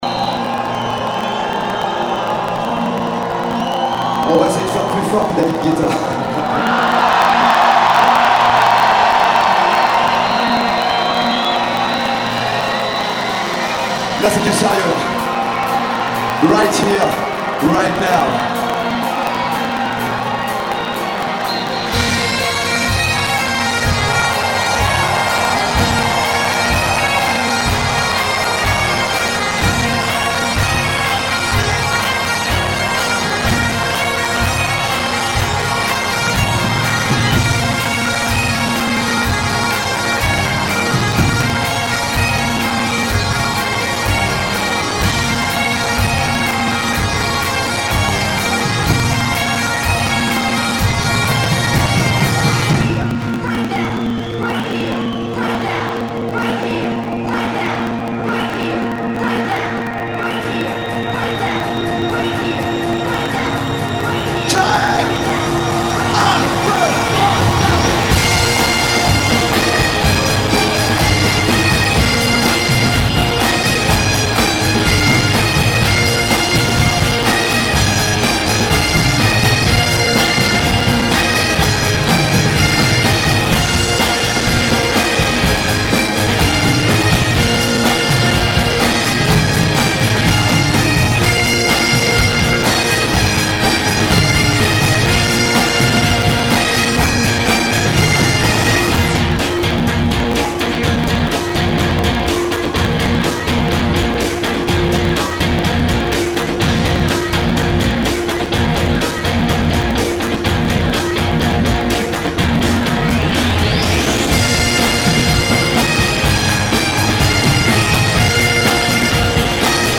Un court extrait de la performance.
au festival des Vieilles Charrues (Carhaix)
Samedi 16 Juillet 2011, scène Kerouac